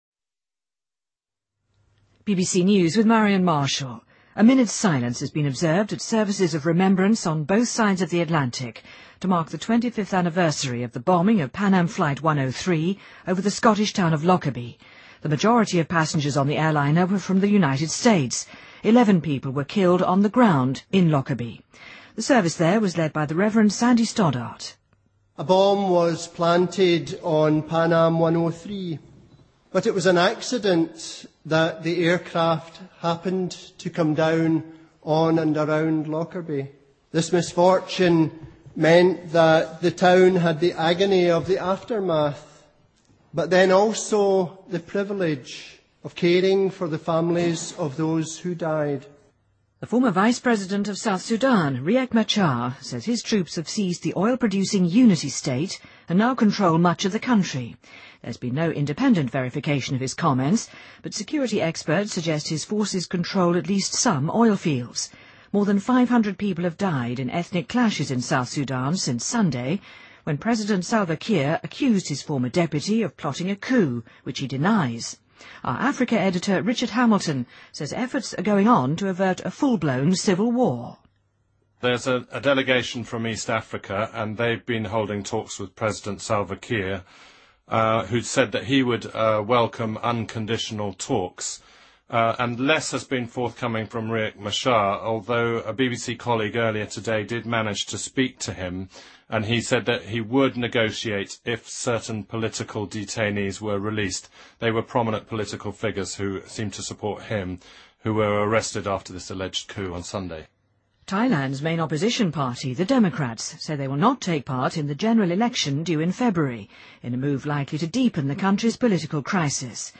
BBC news,美英纪念泛美航空103飞机在苏格兰洛克镇爆炸25周年